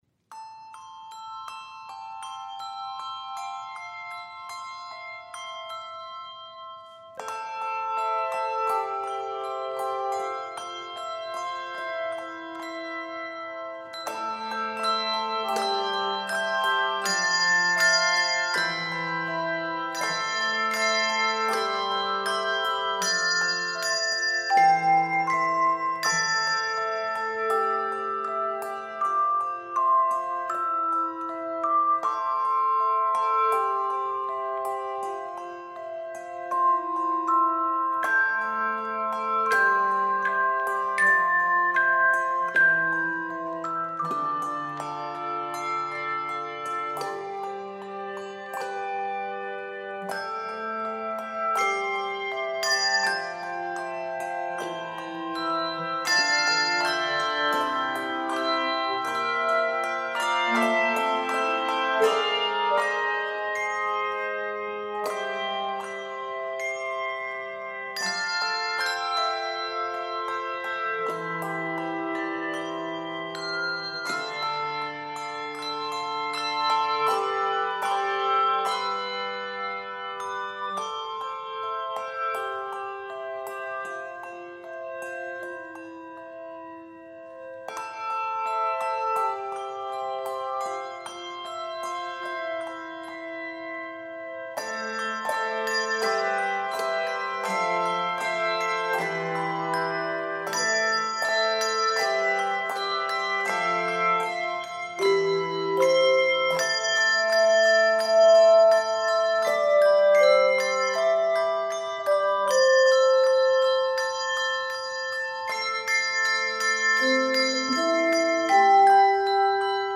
This contemplative arrangement